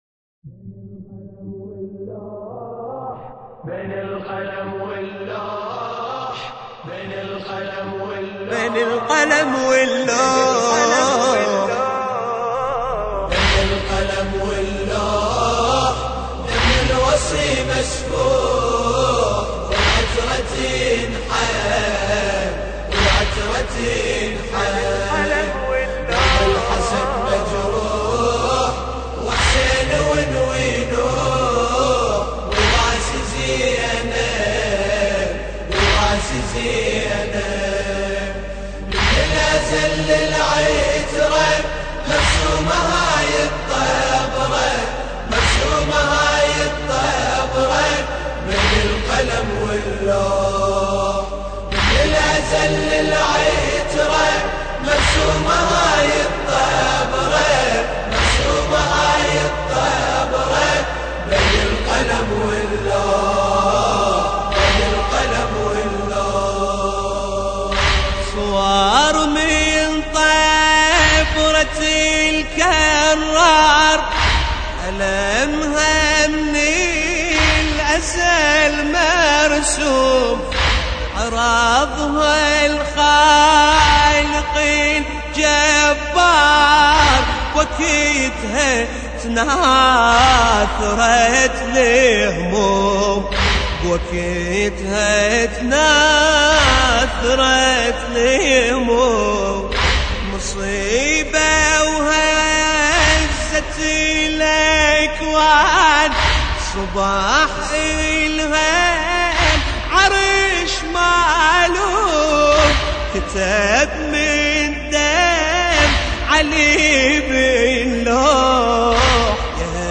مراثي الامام علي (ع)